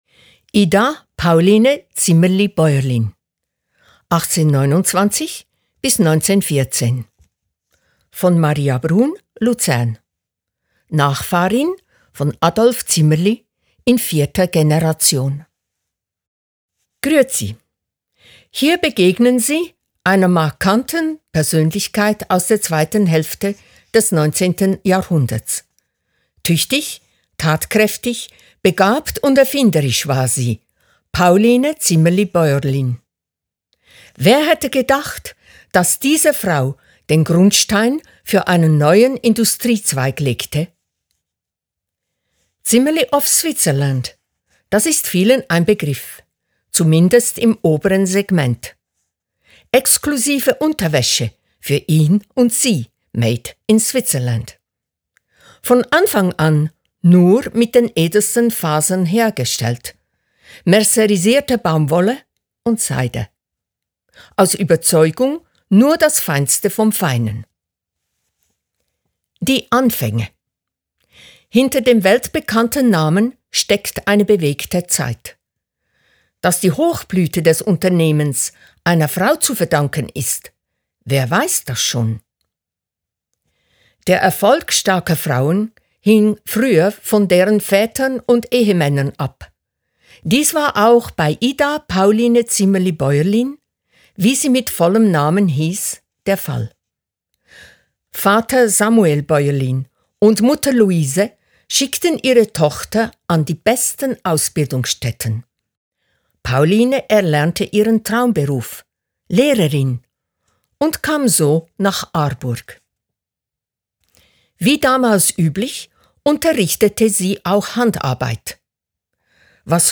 Verfasst und gelesen von